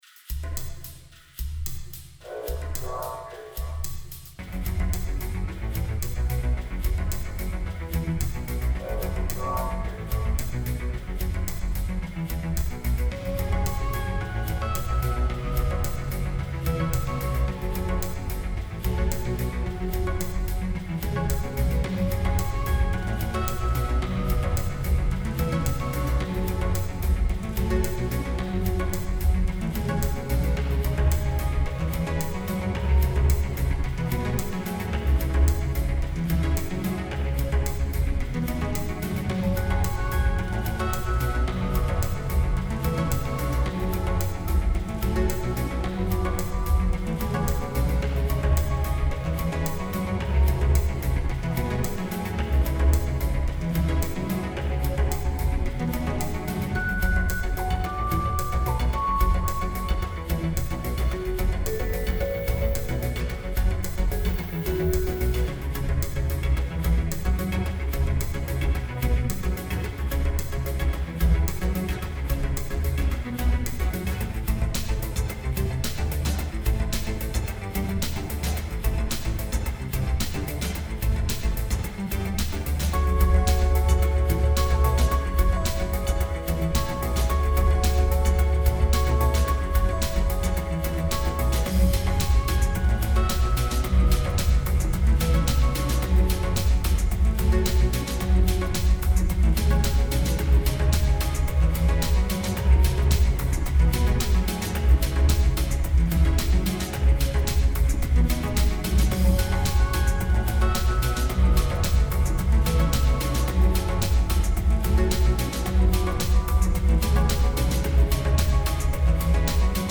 Two instrumental tracks